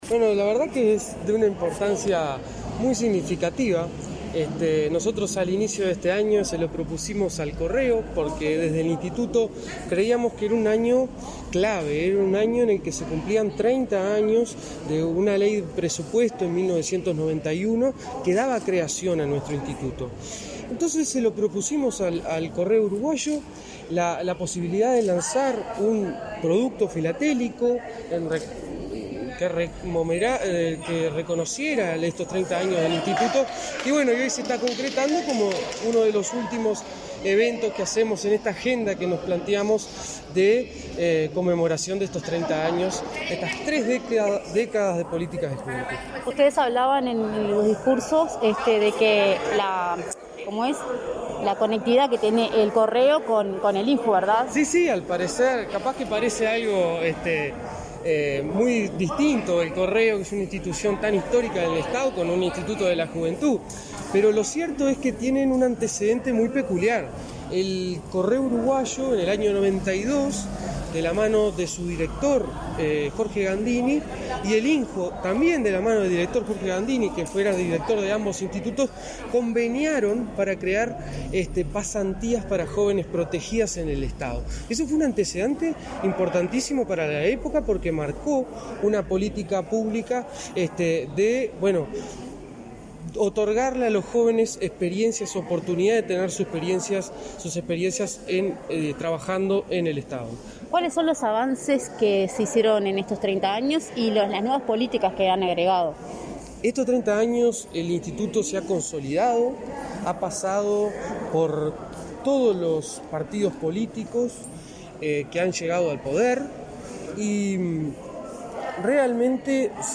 Entrevista al subdirector del INJU, Aparicio Saravia